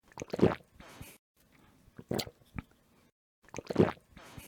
drinkingSound.ogg